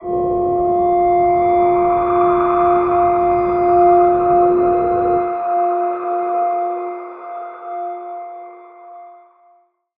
G_Crystal-F5-pp.wav